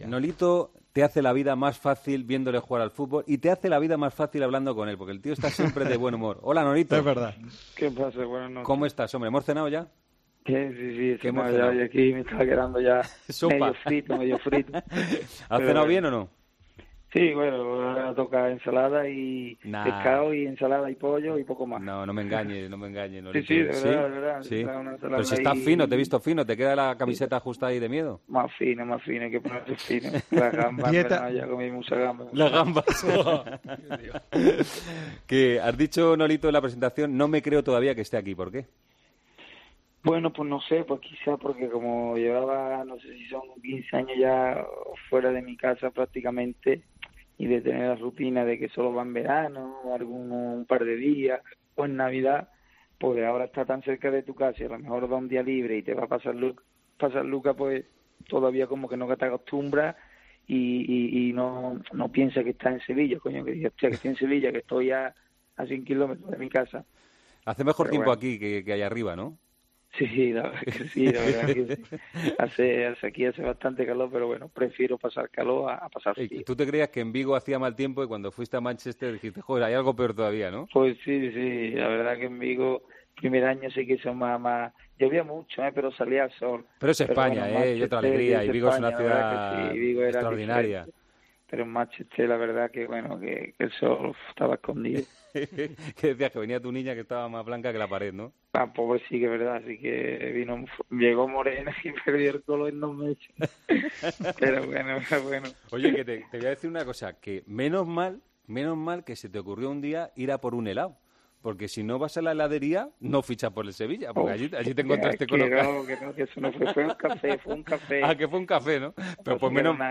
Entrevistas en El Partidazo de COPE